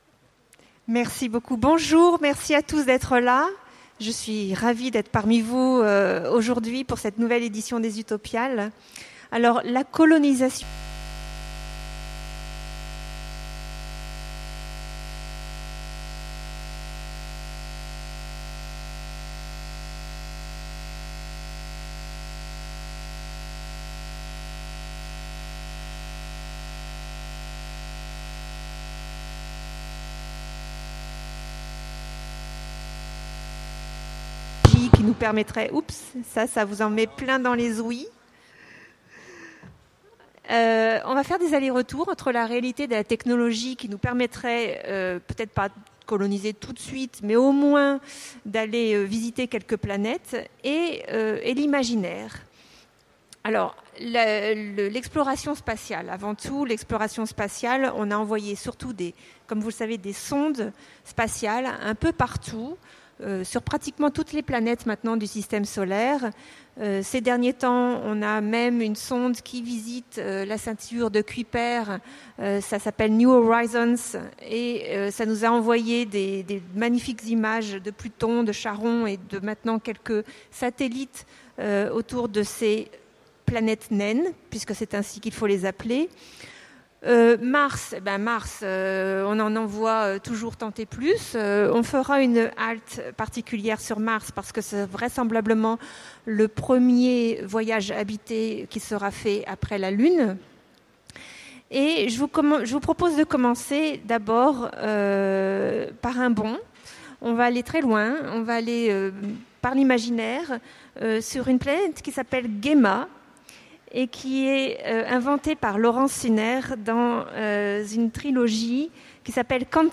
Utopiales 2015 : Conférence Des sondes robotisées aux colonisations humaines